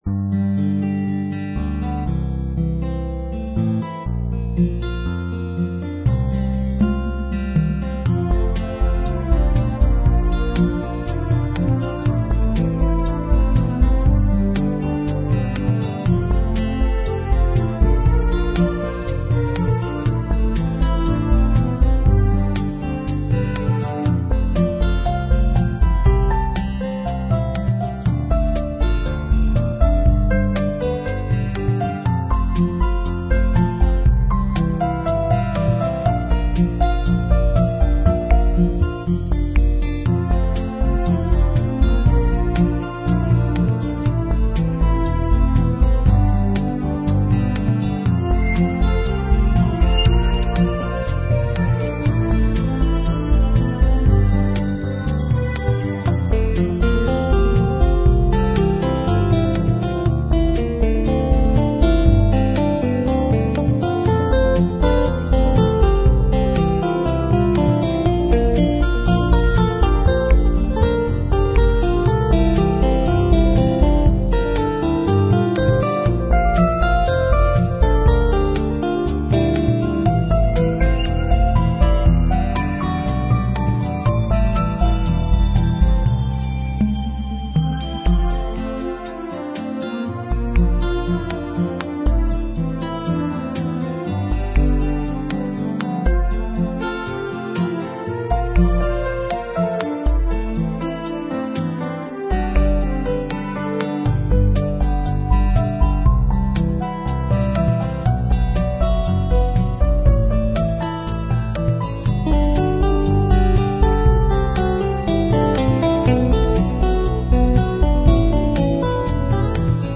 Relaxed Soundtrack music in style of Chillout